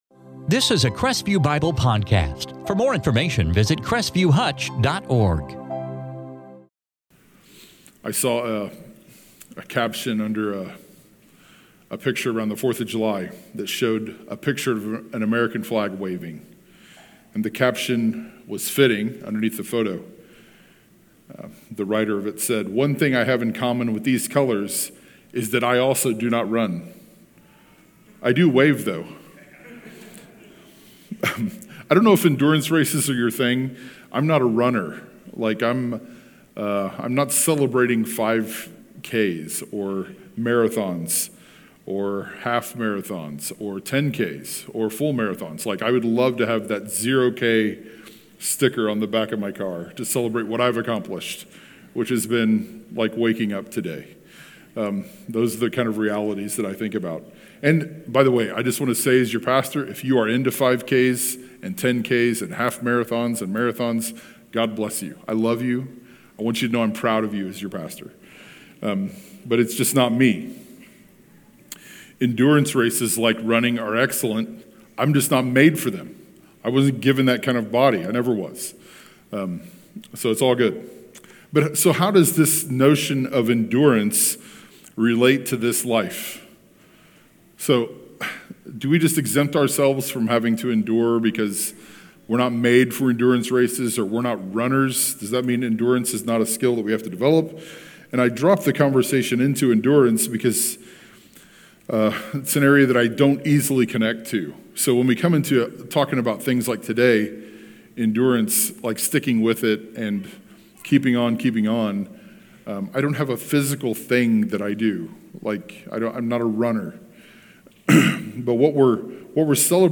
In this sermon from 2 Corinthians 4:7-18